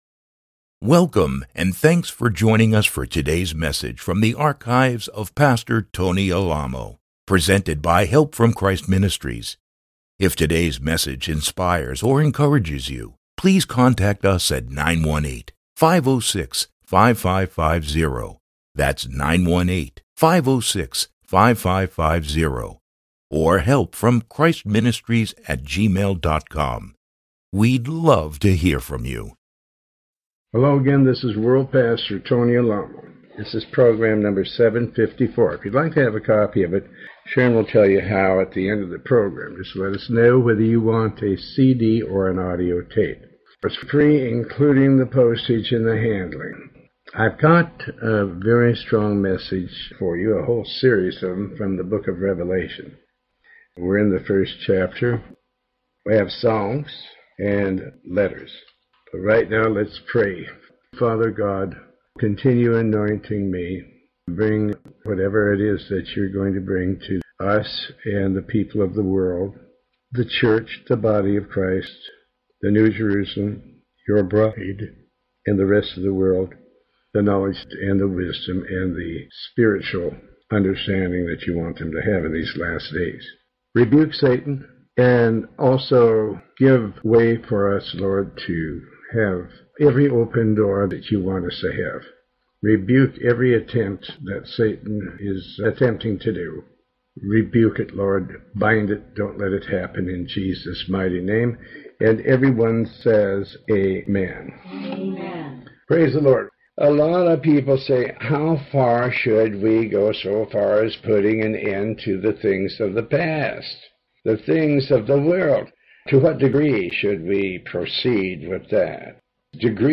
Sermon 754B